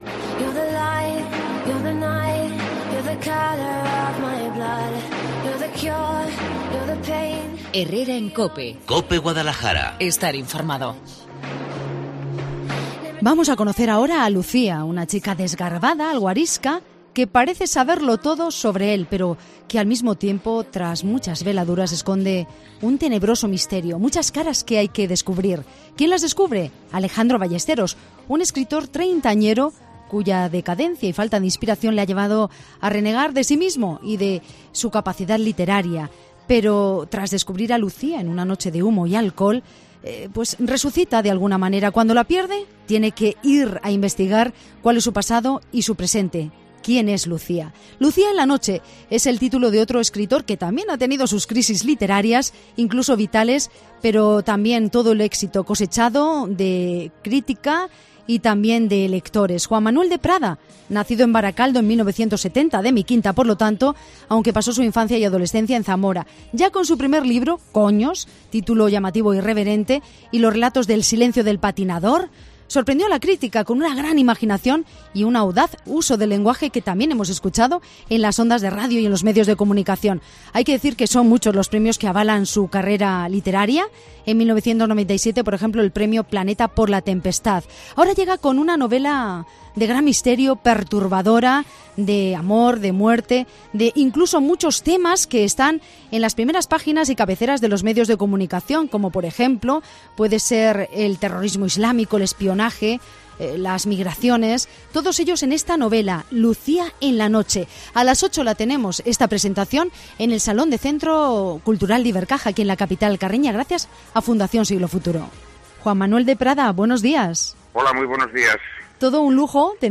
En Cope Guadalajara hemos charlado con su autor.